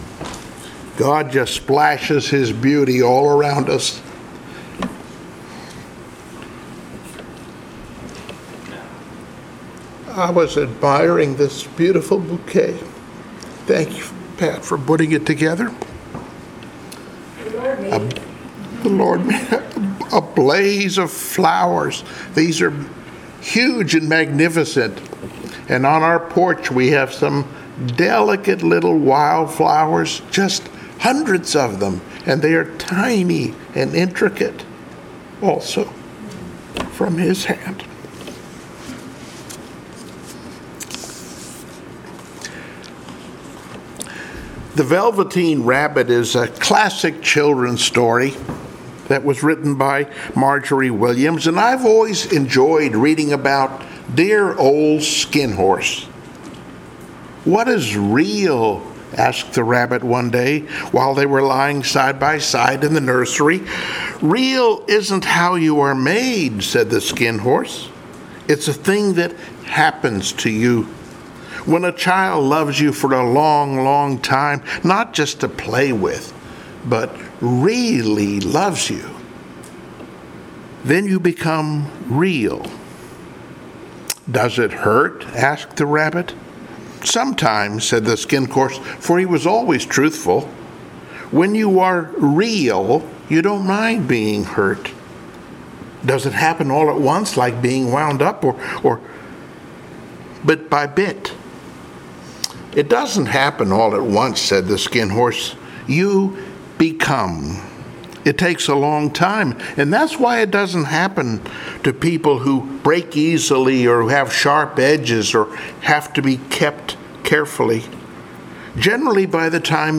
Passage: Psalm 17:1-8 Service Type: Sunday Morning Worship Download Files Notes Bulletin Topics